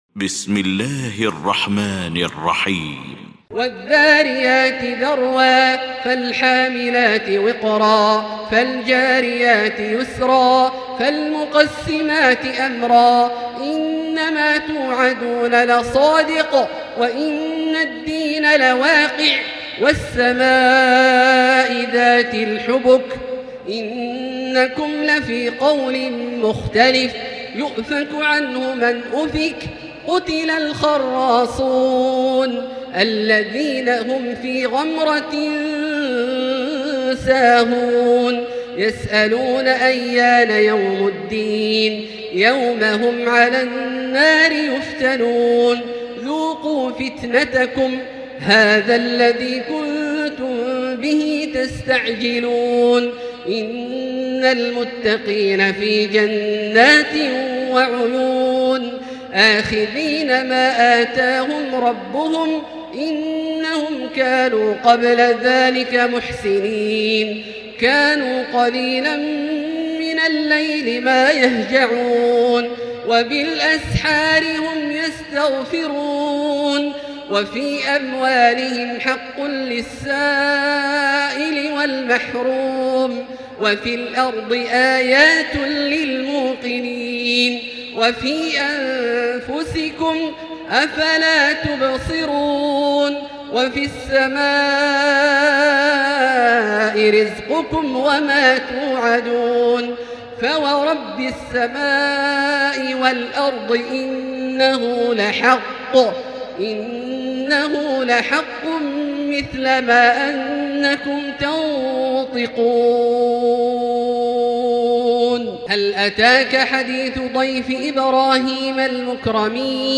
المكان: المسجد الحرام الشيخ: فضيلة الشيخ عبدالله الجهني فضيلة الشيخ عبدالله الجهني الذاريات